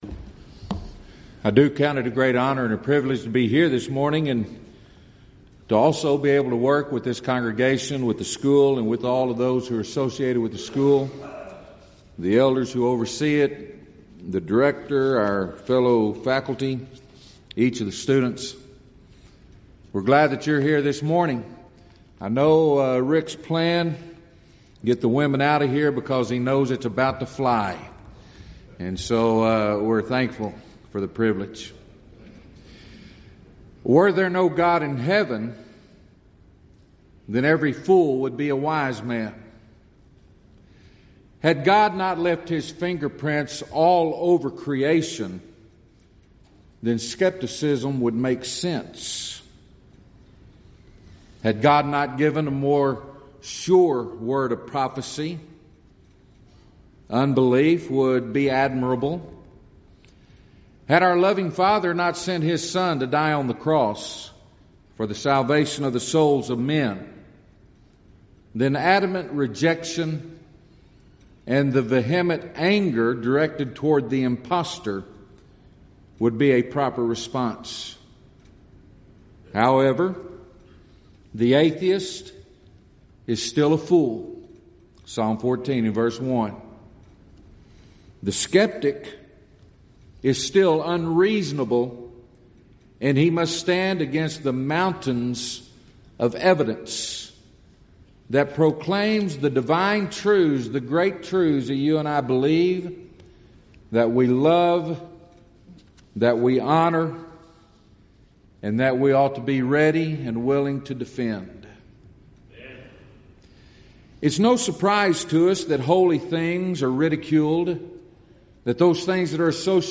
Event: 30th Annual Southwest Bible Lectures
If you would like to order audio or video copies of this lecture, please contact our office and reference asset: 2011Southwest15